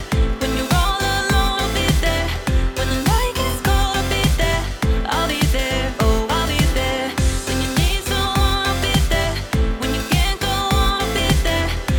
Below are some examples of the AI Loudener being applied to a few unmastered tracks.
Original track -14.2 LUFS